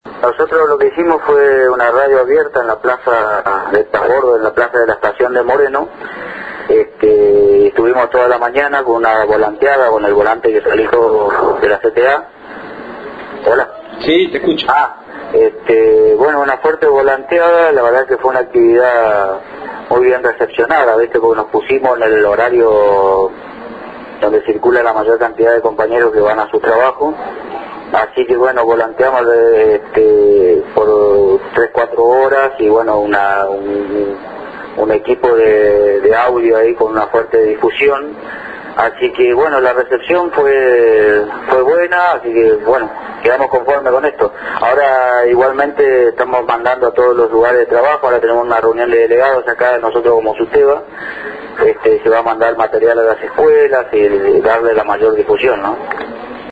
Los compa�eros de la CTA Moreno realizaron una radio abierta en la estaci�n de transbordo de Moreno durante la ma�ana de hoy.